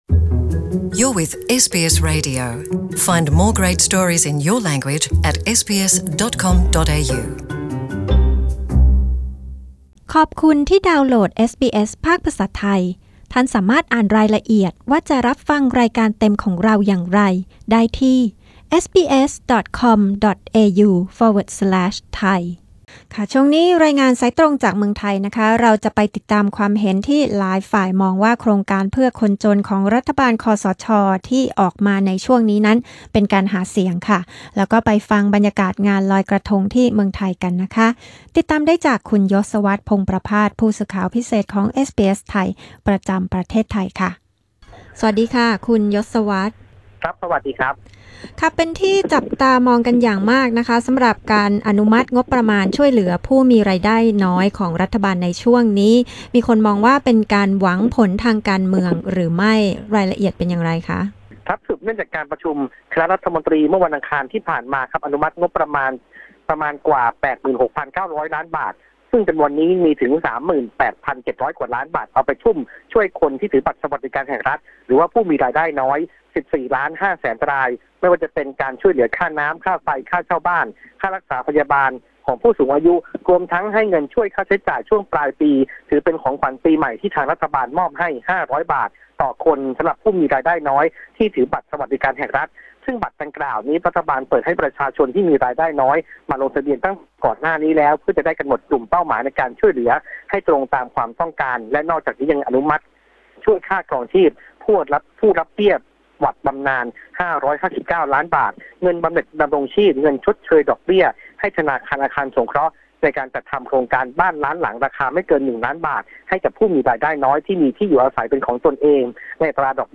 Thai phone-in news 22 NOV 2018